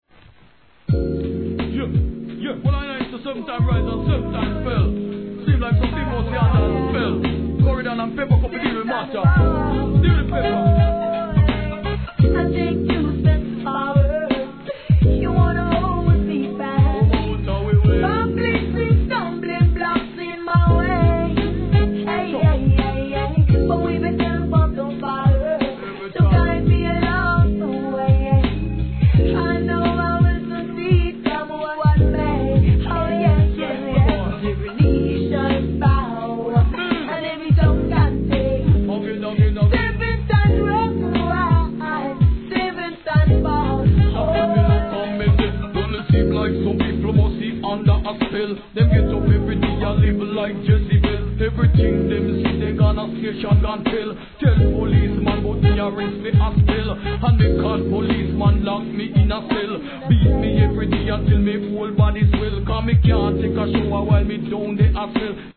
REGGAE
R&B調の綺麗なサウンドに乗せるバランスの取れたGOODコンビネーション!